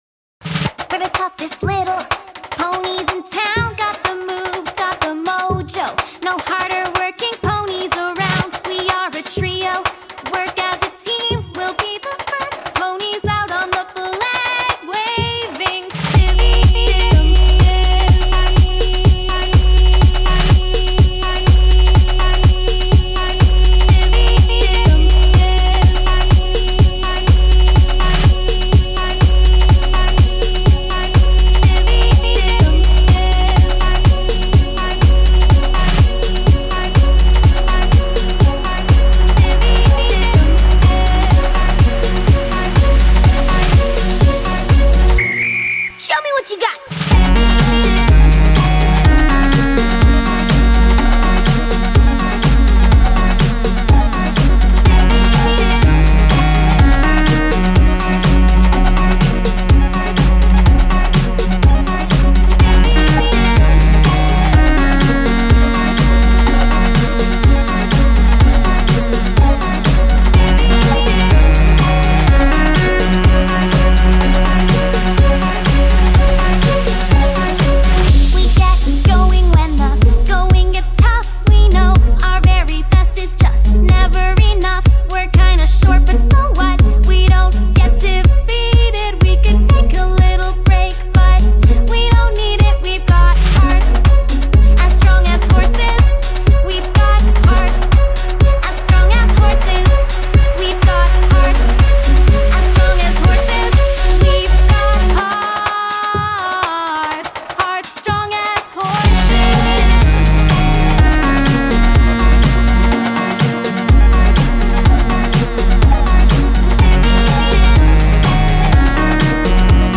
Had to remix this song right when I heard it !